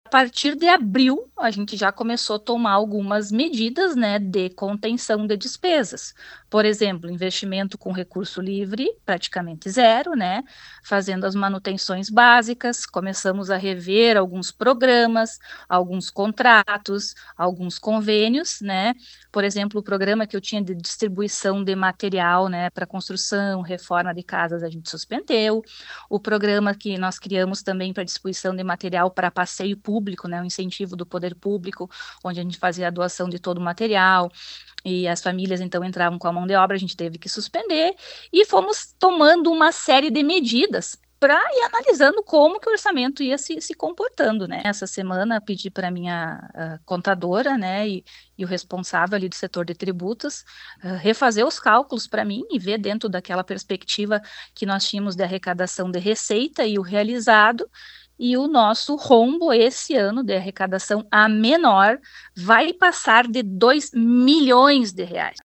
(Abaixo, áudio de Flaviana).